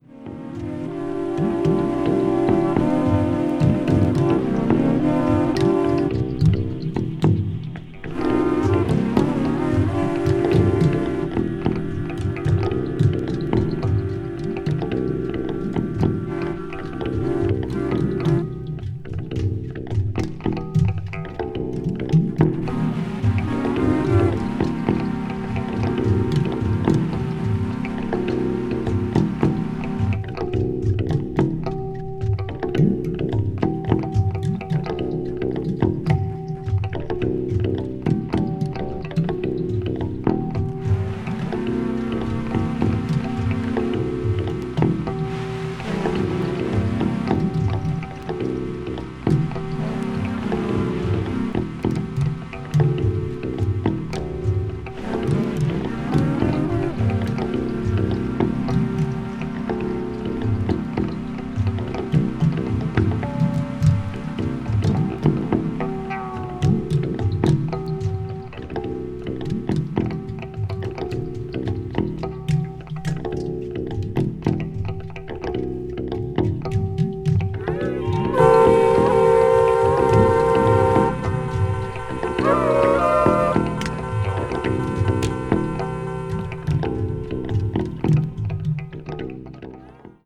ambient   avant garde   electronic   experimental   minimal